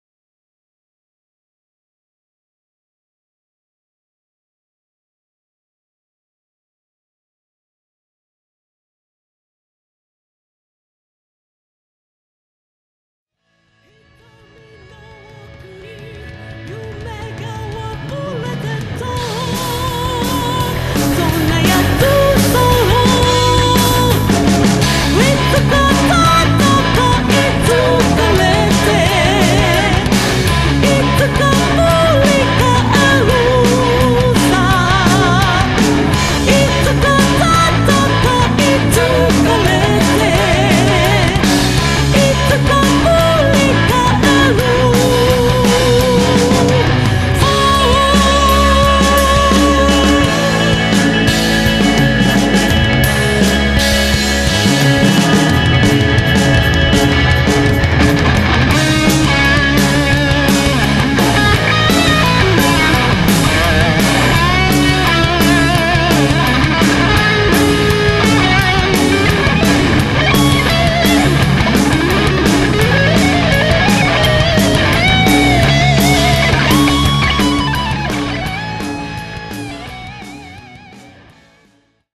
メロディアス・ハード